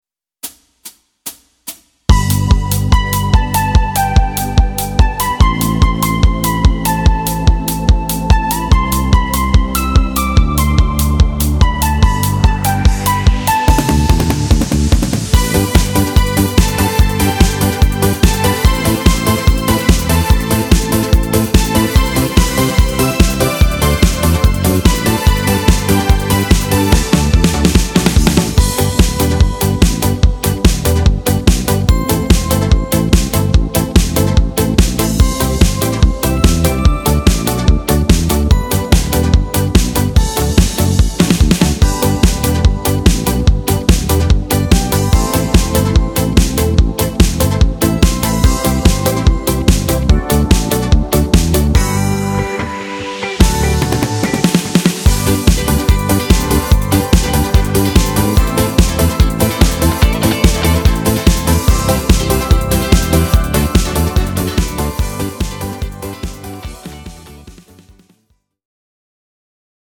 w wersji instrumentalnej dla wokalistów
Disco Polo